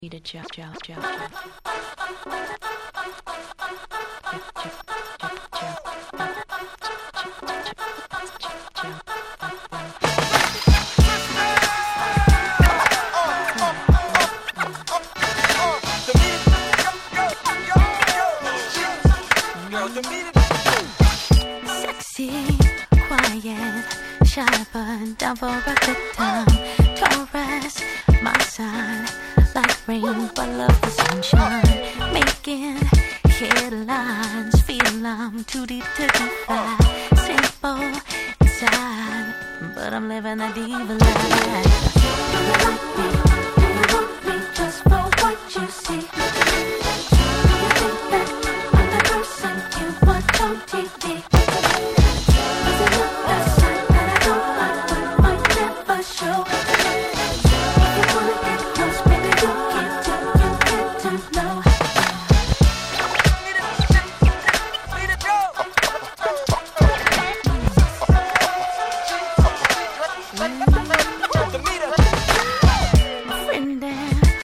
話はそれましたが、本作も所々Neo Soul風味を感じさせる良曲がちらほら。